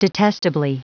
Prononciation du mot detestably en anglais (fichier audio)
Prononciation du mot : detestably
detestably.wav